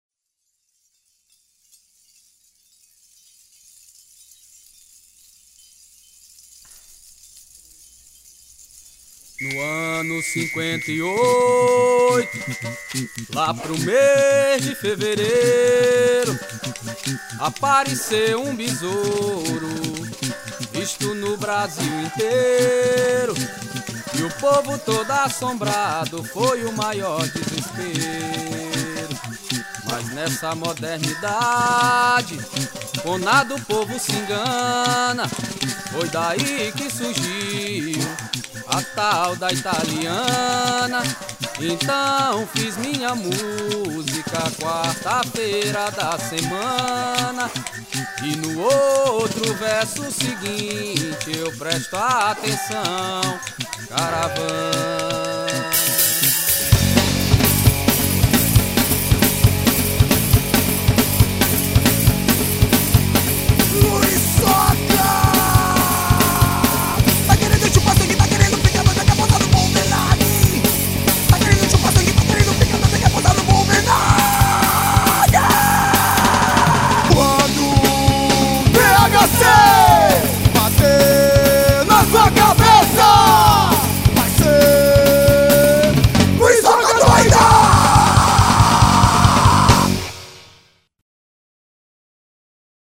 1949   01:32:00   Faixa:     Rock Nacional